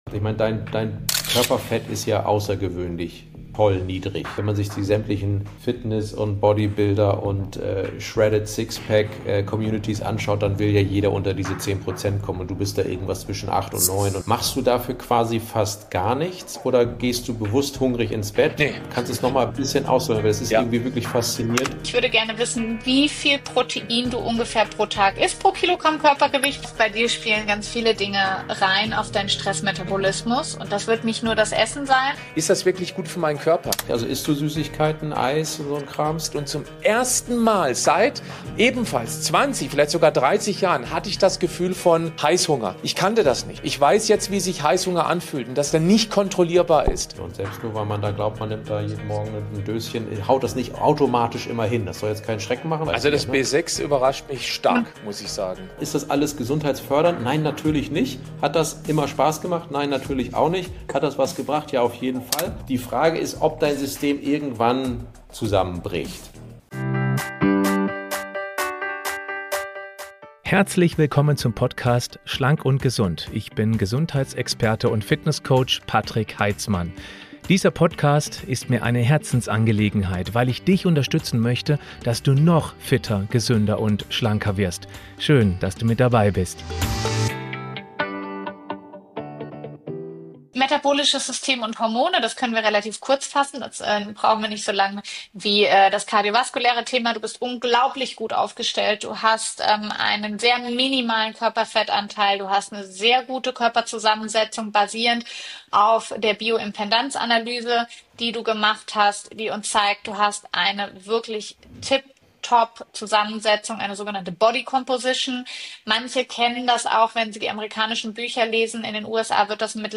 🔬 Teil 2: Meine Longevity-Ergebnisse – Was Mikronährstoffe, Hormone, Stress & Schlaf wirklich über meine Gesundheit verraten Willkommen zum zweiten Teil meines tiefgehenden Interviews mit Dr. med.